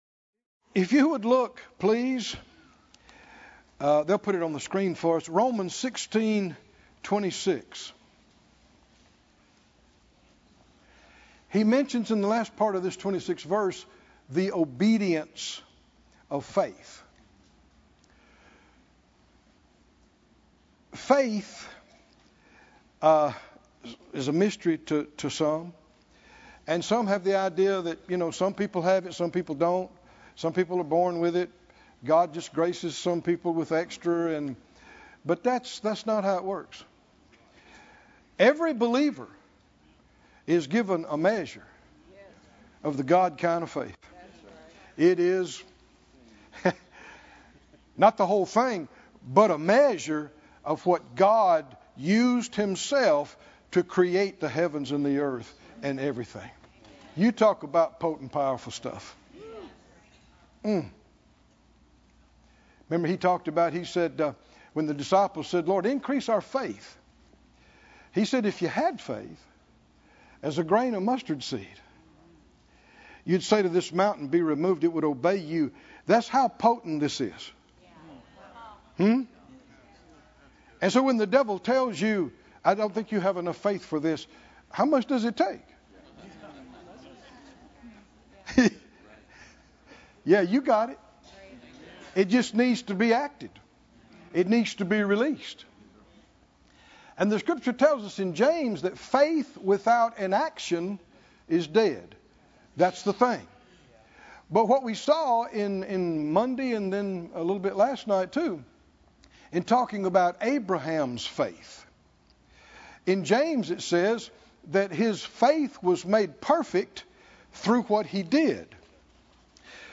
The Greater Faith Conference 2025: The Obedience Of Faith - Pt. 3 - Partial Obedience